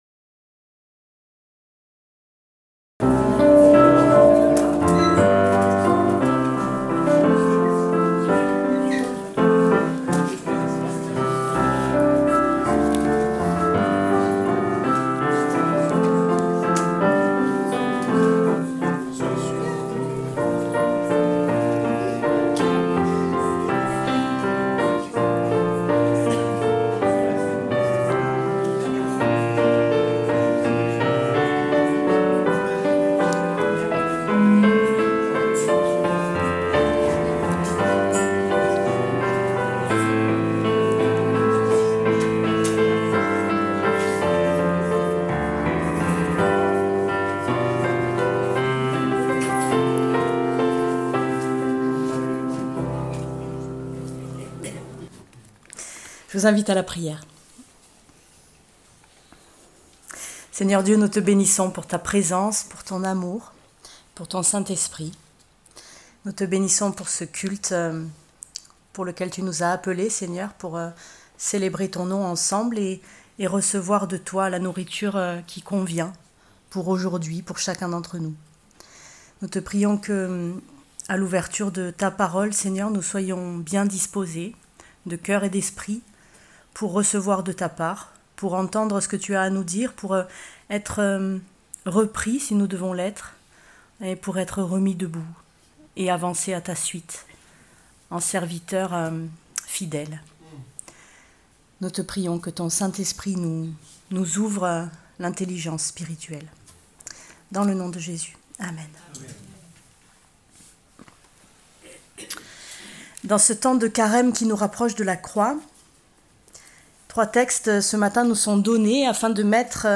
Prédication du 08 mars 2026.